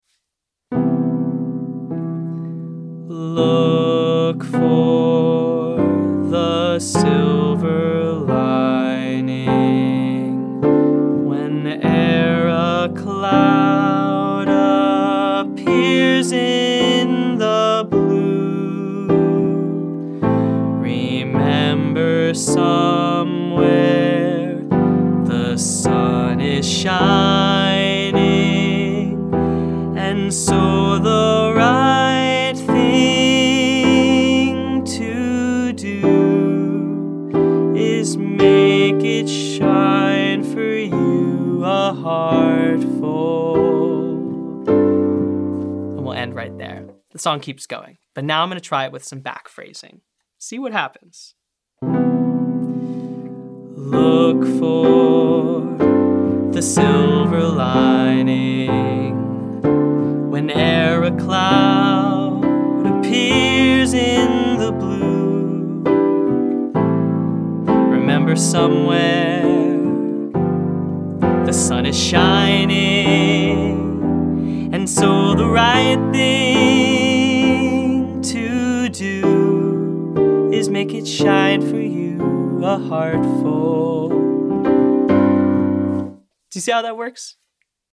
Day 14: Back Phrasing - Online Singing Lesson
Back phrasing, is when we purposely don’t sing the melody on the beat, but instead speed up or slow down certain parts of the melody so the notes fall before, or after the beat, or a particular chord.